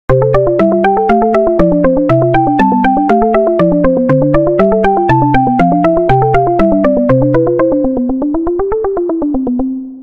画面切り替えで使用できる短い音楽です。明るい音楽。